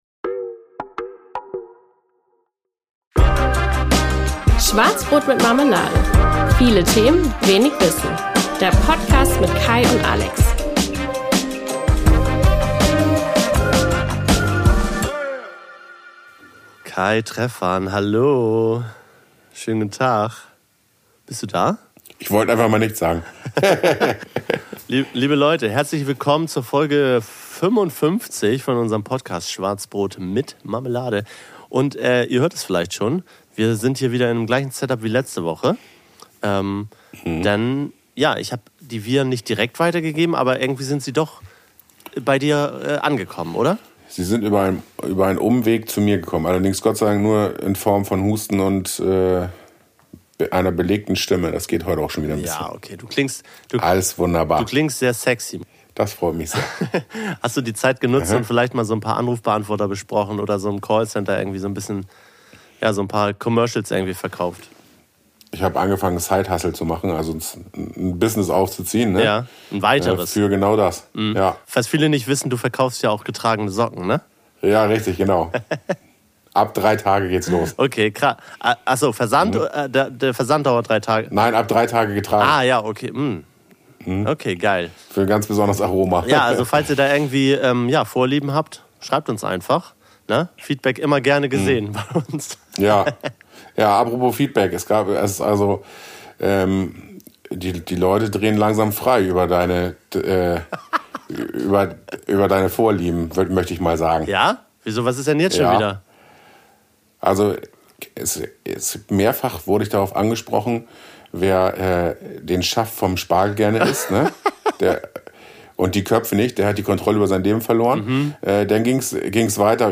Freut euch auf eine nasale, aber dennoch unterhaltsame Folge, die definitiv die gewisse Prise „Aroma“ bietet.